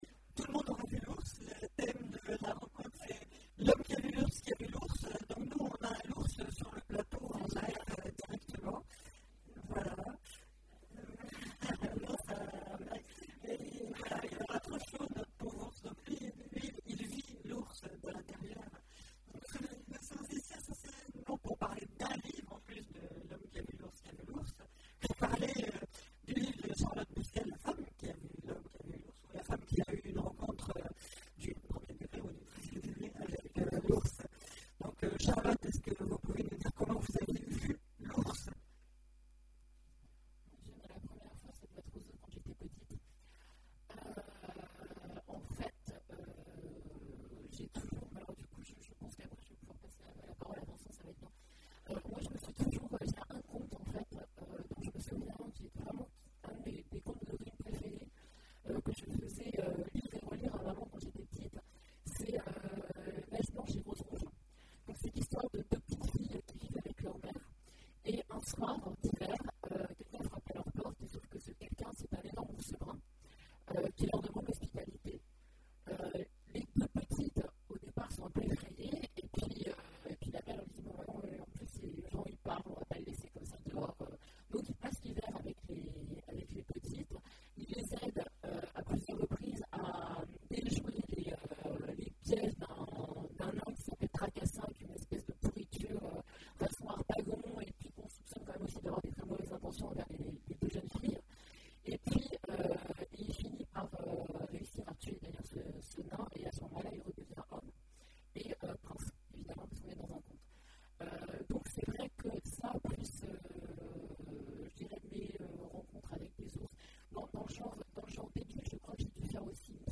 Imaginales 2014 : Conférence L'homme et la femme, qui ont vu l'ours!